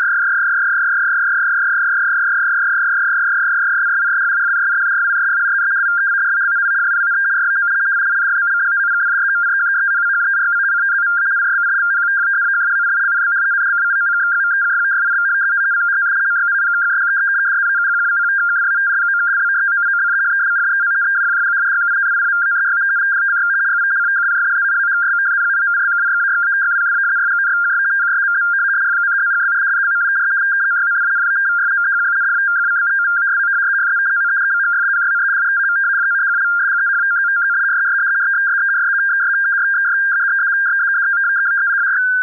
Неизвестная передача
Подумал, что SSTV, но нет